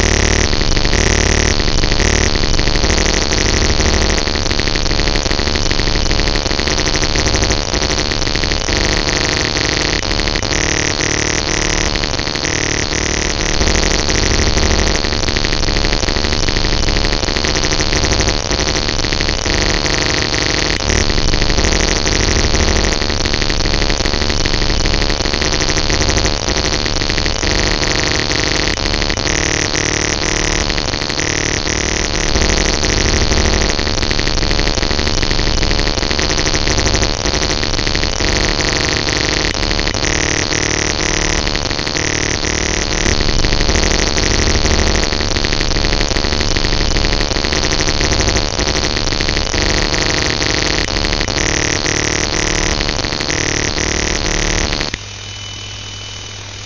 Recordings of digital numbers stations